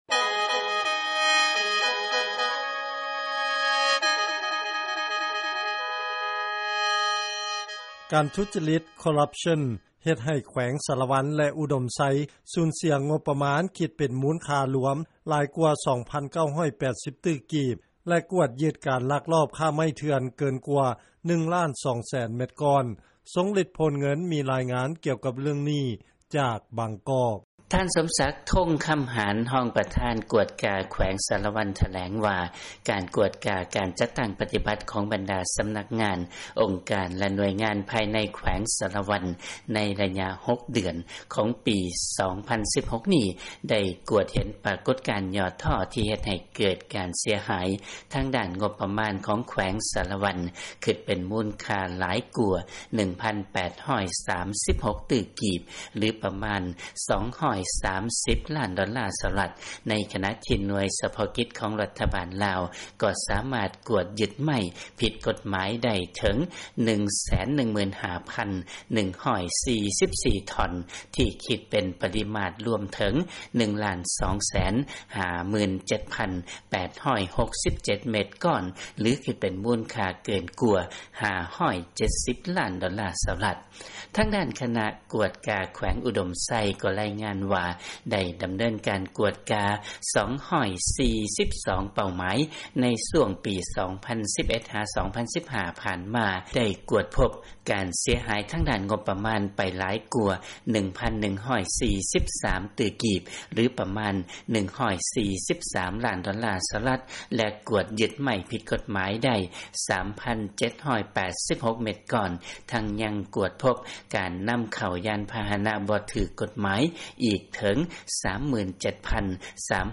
ເຊີນຟັງລາຍງານ ການທຸຈະລິດ ເຮັດໃຫ້ ແຂວງສາລະວັນ ແລະ ອຸດົມໄຊ ສູນເສຍງົບປະມານ ຫຼາຍກວ່າ 2,980 ຕື້ກີບ